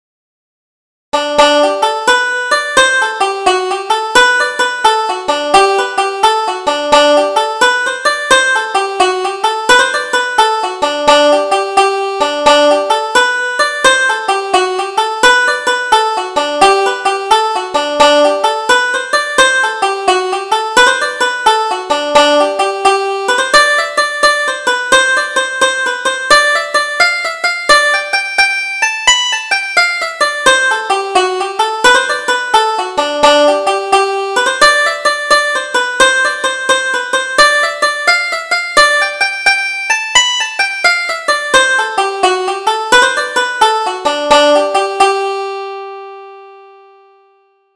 Double Jig: Jackson's Maid